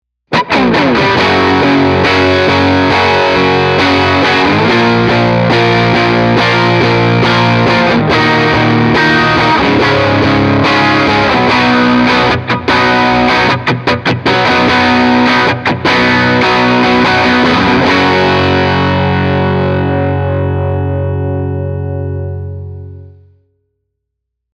Tutte le clip audio sono state registrate con testata a valvole artigianale da 15W ispirata al Cornell Romany e cassa 1×12 equipaggiata con altoparlante Celestion A-Type impostato su un suono estremamente clean.
Chitarra: Fender Stratocaster (pickup al ponte)
Turbo: ON
Engine: 9/10